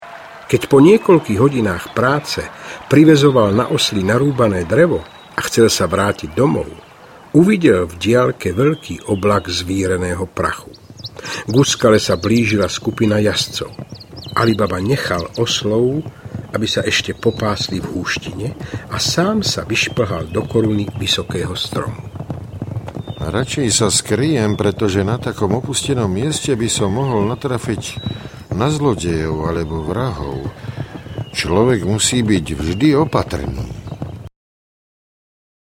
Najkrajšie rozprávky 5 audiokniha
Obsahuje rozprávky Alibaba a štyridsať lúpežníkov a Studňa zlodejov, v podaní výborného Mariána Labudu.
Ukázka z knihy